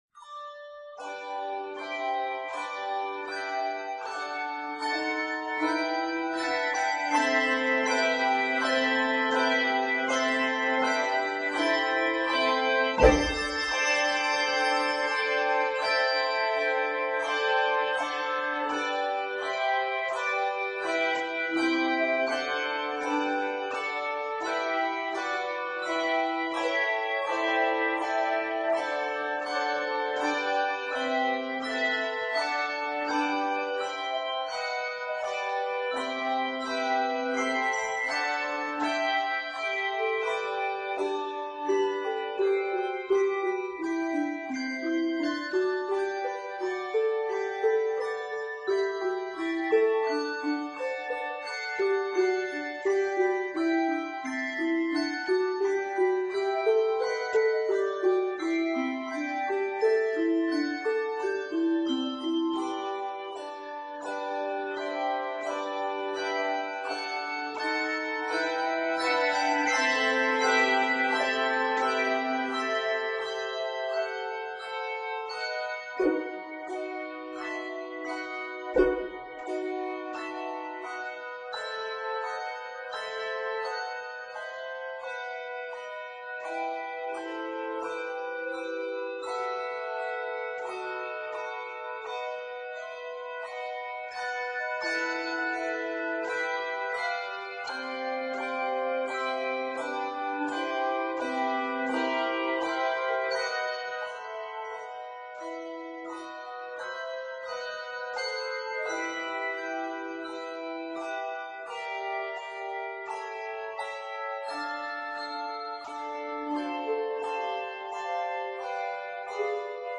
handbells
triumphant setting